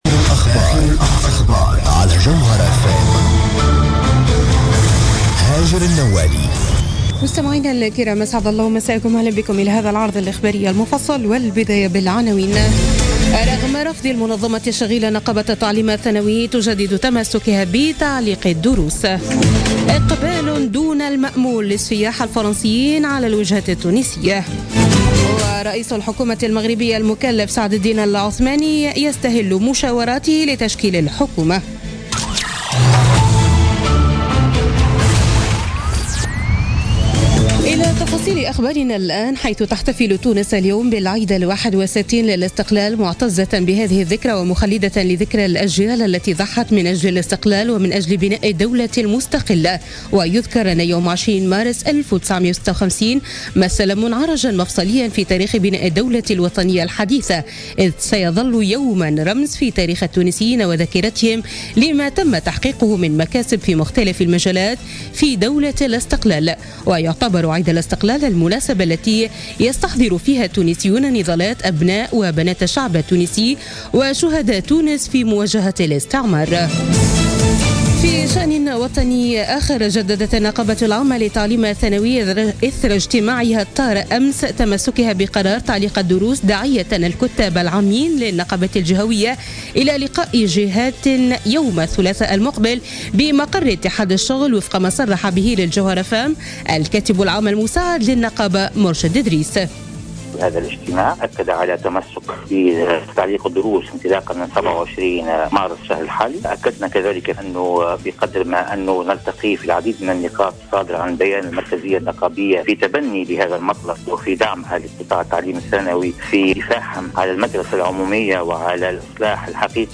نشرة أخبار منتصف الليل ليوم الإثنين 20 مارس 2017